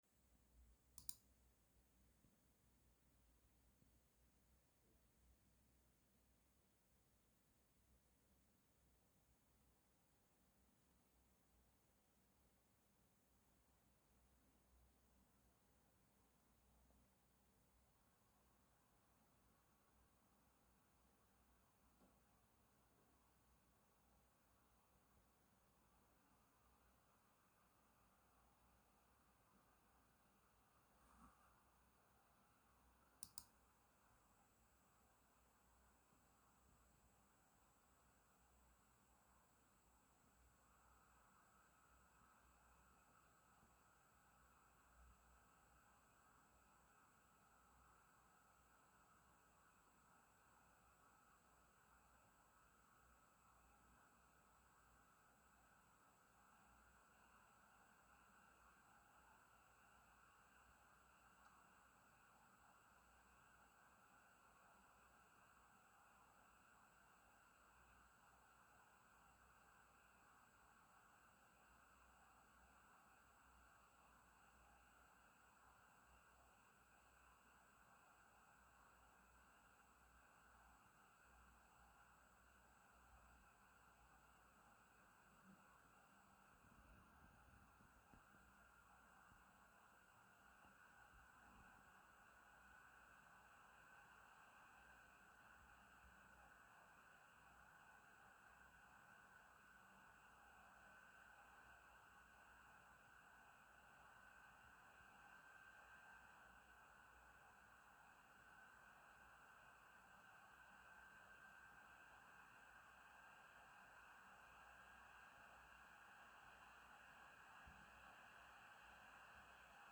Um die Geräuschkulisse besser zu dokumentieren finden sich nachfolgend drei Tonaufnahmen, die den Flüstermodus, das Standardprofil und den Leistungsmodus abbilden. Die Aufnahme erfolgte mit 40 Zentimetern zur Gehäusefront aus dem Leerlauf in einen CPU-Volllast-Benchmark.
Dadurch ändert sich auch das Geräusch, was mitunter als nervig empfunden werden kann.